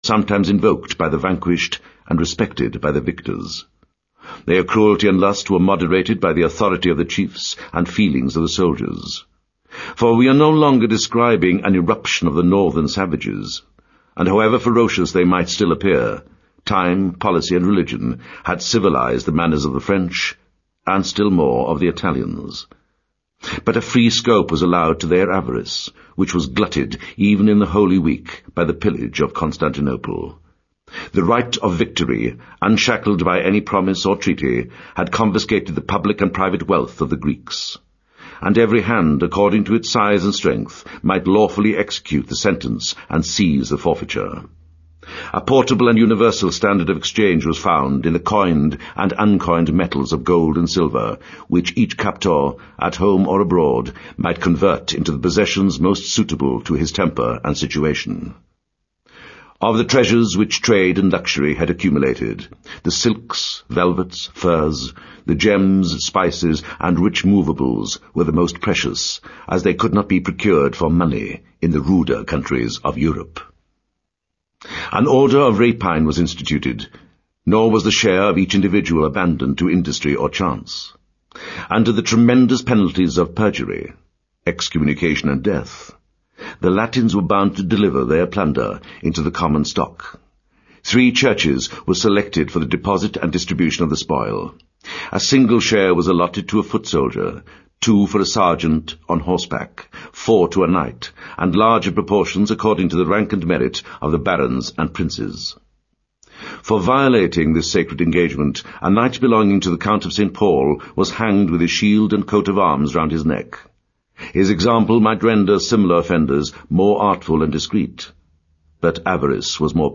在线英语听力室罗马帝国衰亡史第二部分：56的听力文件下载,有声畅销书：罗马帝国衰亡史-在线英语听力室